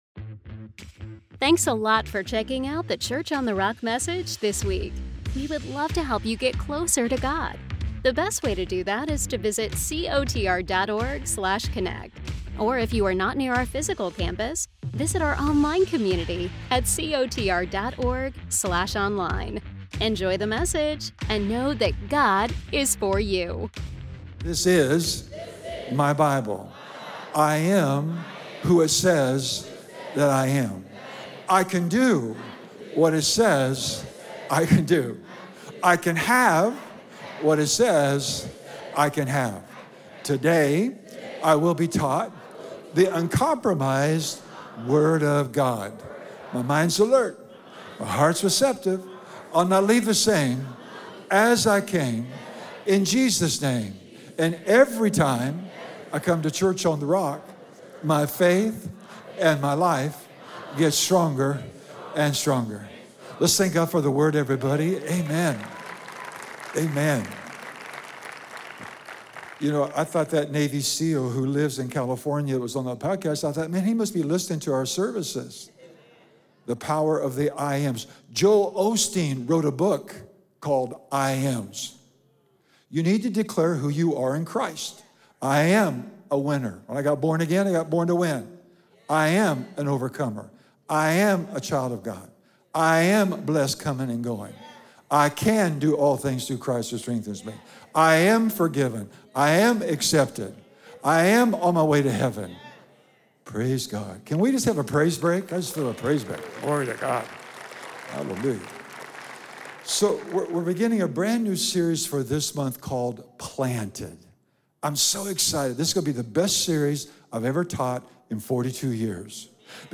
Whether you're facing uncertainty, transition, or just feel spiritually dry—this teaching will inspire you to go deeper and live fully planted.